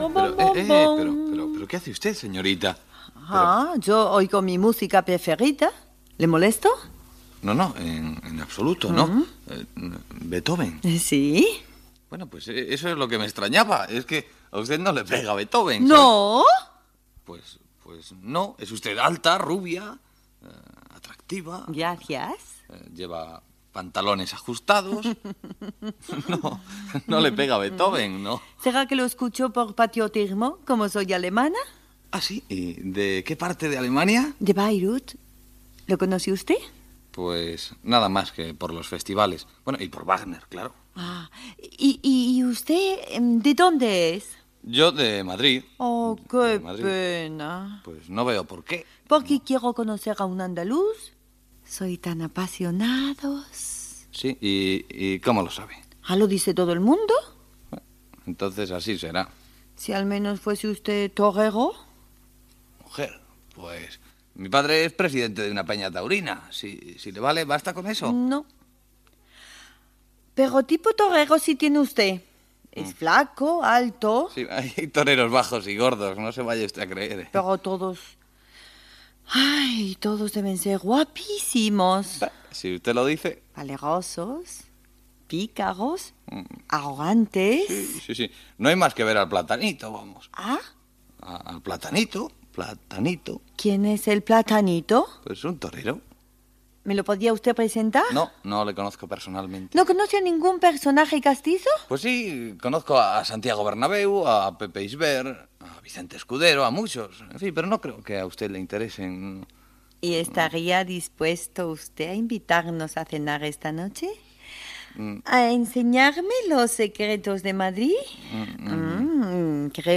Diàleg humorístic del locutor amb una turista alemanya.